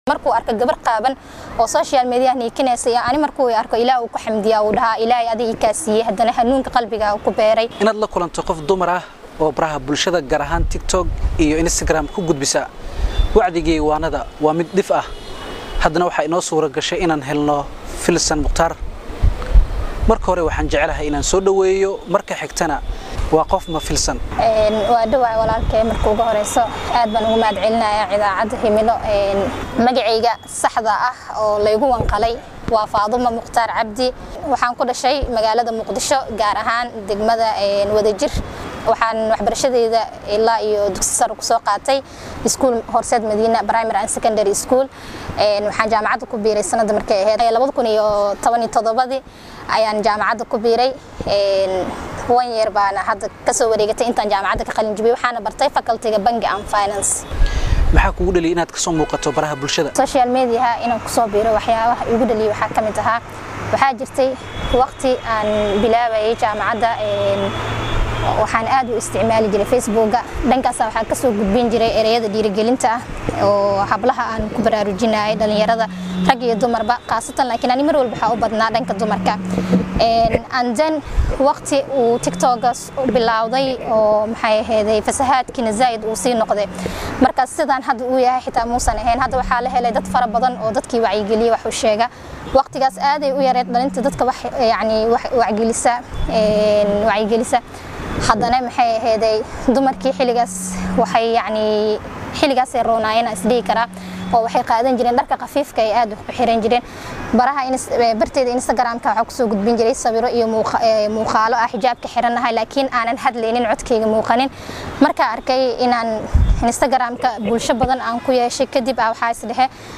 Warbixinta-Gabadha-Baraha-bulshada-mp3.mp3